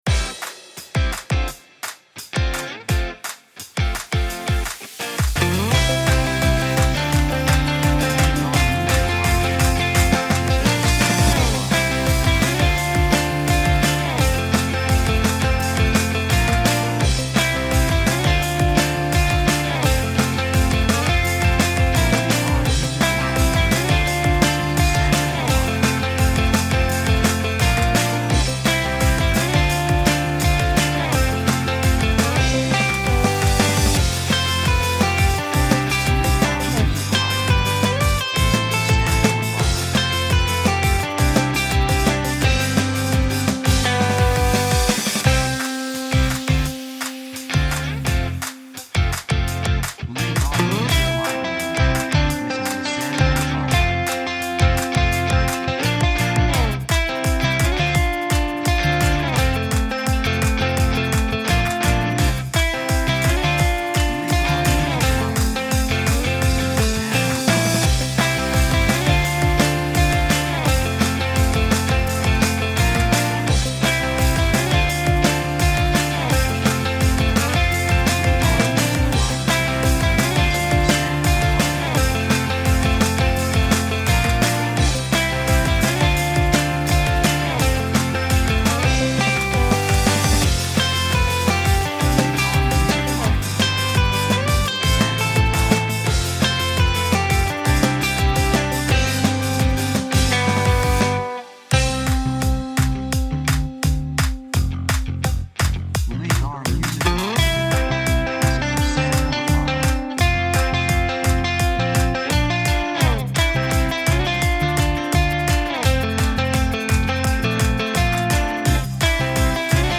2:45 170 プロモ, ロック